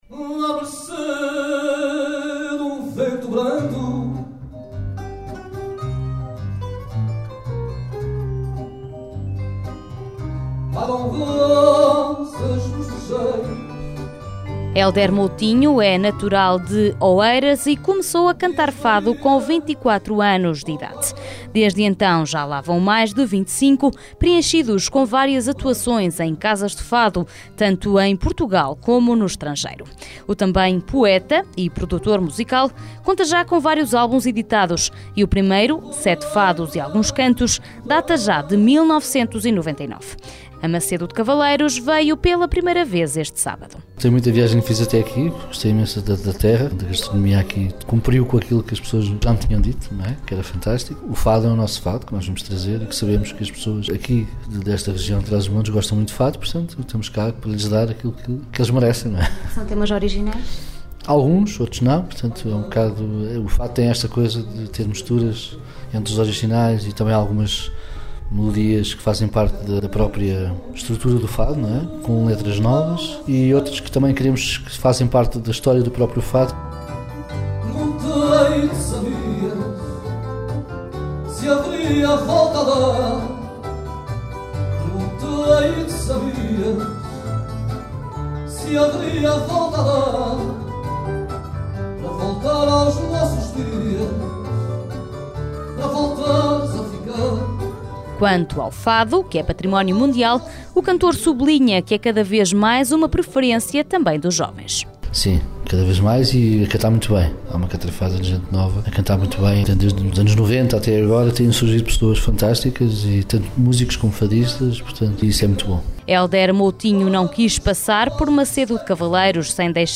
No sábado, o Centro Cultural de Macedo de Cavaleiros abriu as portas ao fado na voz de Hélder Moutinho.
O Fado no Masculino com Hélder Moutinho, uma proposta musical que este sábado atraiu amantes do fado ao auditório do centro cultural da cidade.